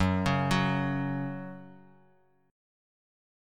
F#5 chord